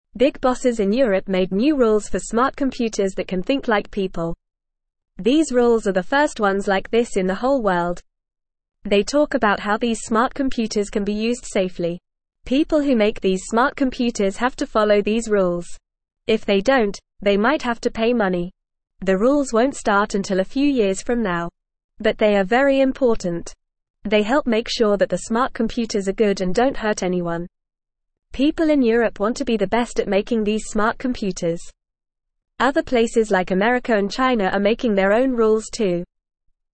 Fast
English-Newsroom-Beginner-FAST-Reading-New-Rules-for-Smart-Computers-to-Keep-People-Safe.mp3